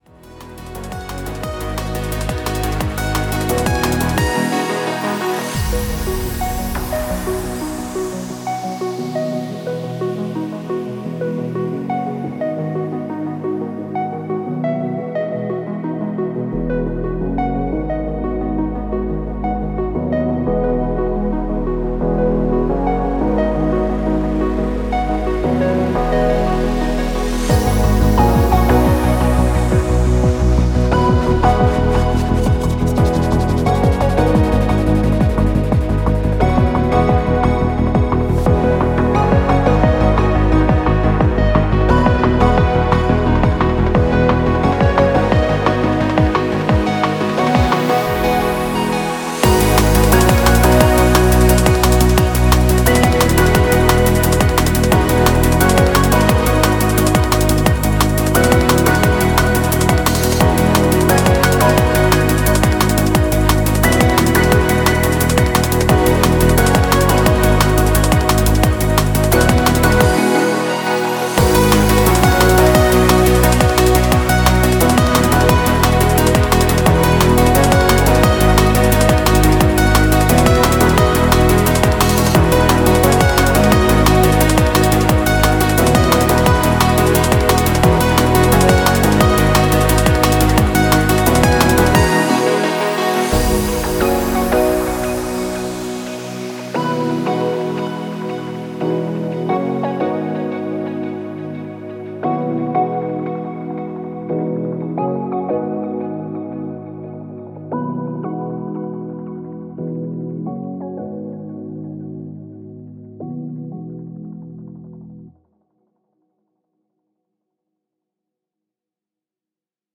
drum and bass
electronic music